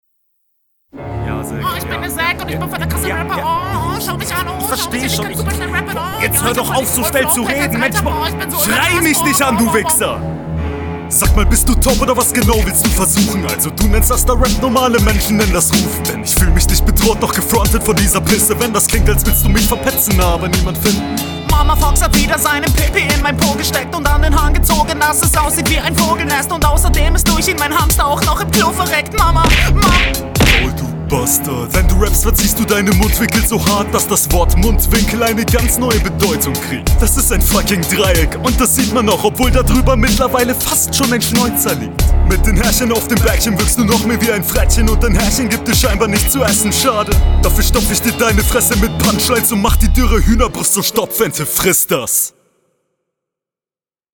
intro sehr gut beat liegt die viel besser is glaube mehr dein style der skit …